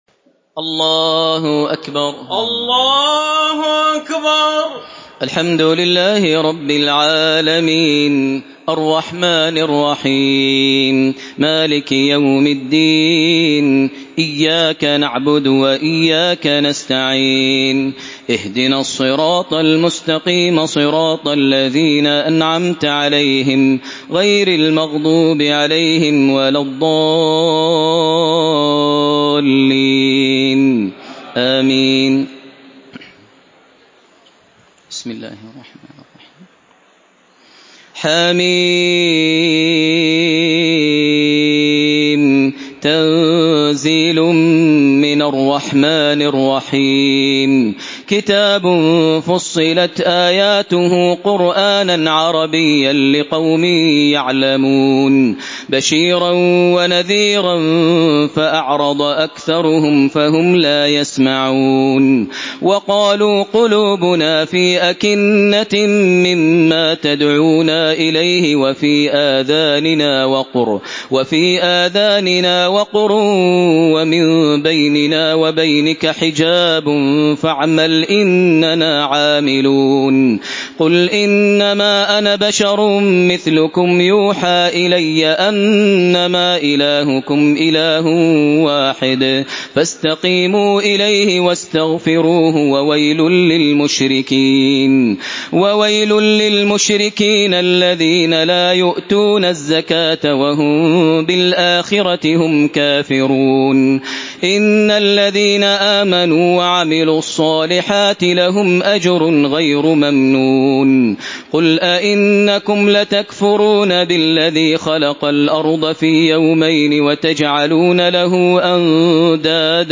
سورة فصلت MP3 بصوت تراويح الحرم المكي 1432 برواية حفص
مرتل